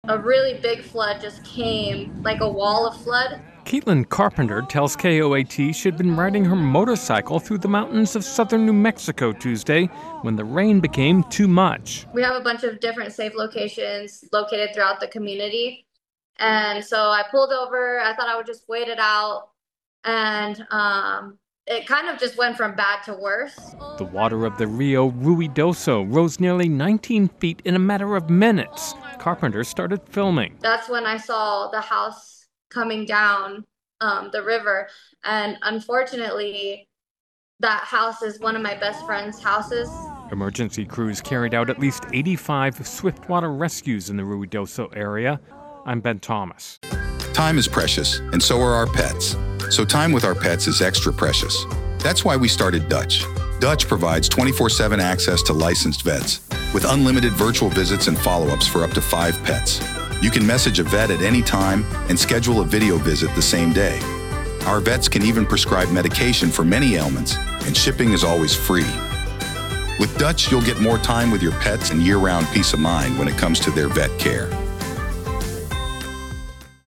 ((opens with actuality))